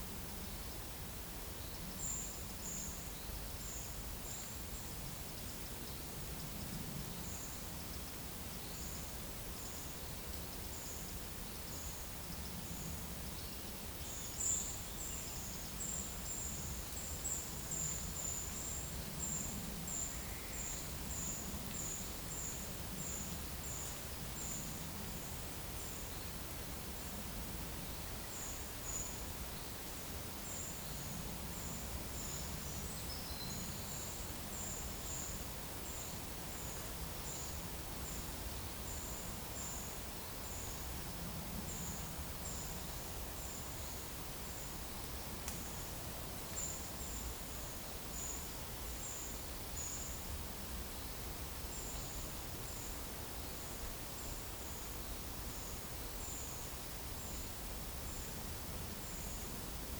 Monitor PAM
Certhia familiaris
Turdus iliacus
Certhia brachydactyla